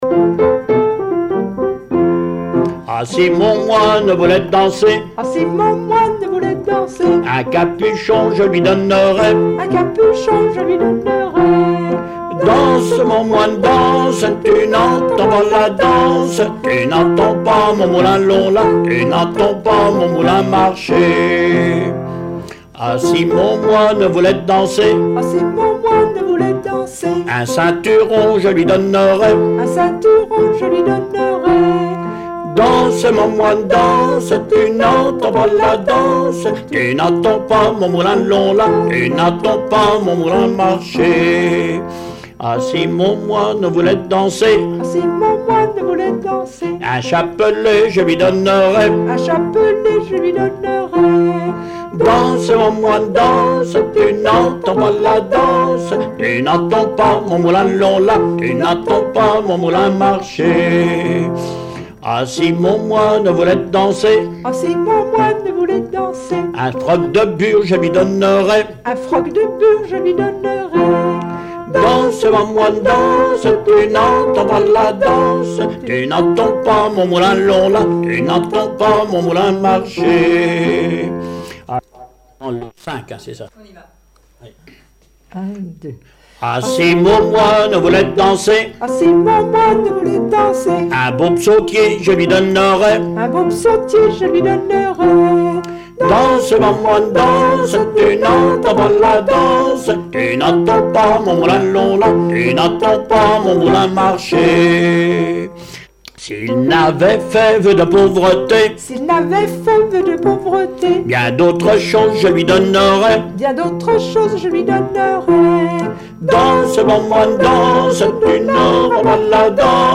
Genre énumérative
Chansons et témoignages
Catégorie Pièce musicale inédite